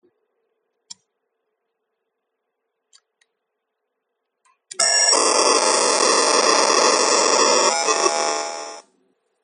TAS2505-Q1: Sporadic loud noise output with WCLK/BCLK error
My customer is designing automotive cluster with TAS2505-Q1. 2 pcs of TAS2505 (relatively low PPM) is reported to output loud noise sporadically but can somehow self recover w/ or w/o reconnect power.
noise.mp3